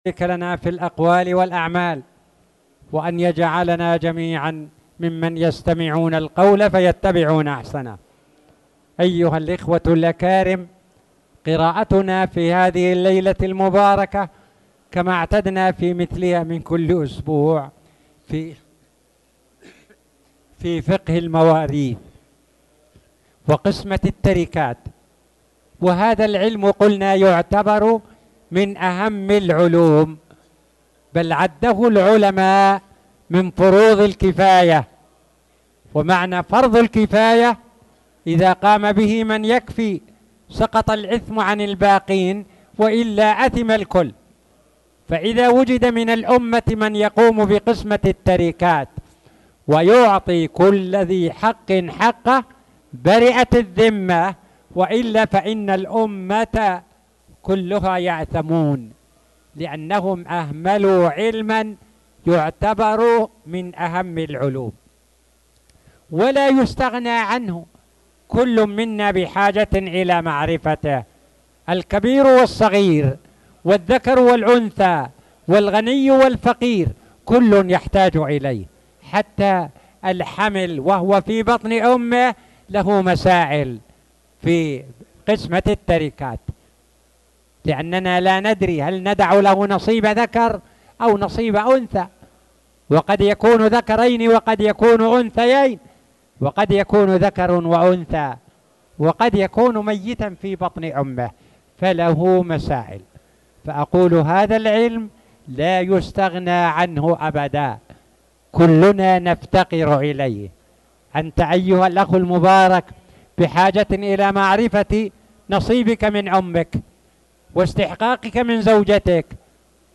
تاريخ النشر ٢ محرم ١٤٣٨ هـ المكان: المسجد الحرام الشيخ